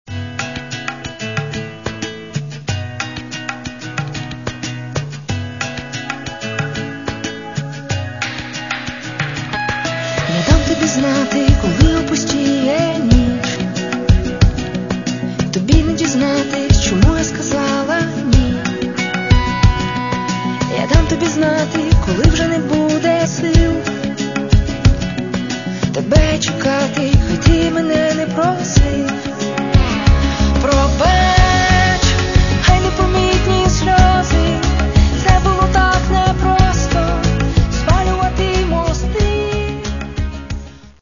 Каталог -> Поп (Легкая) -> Сборники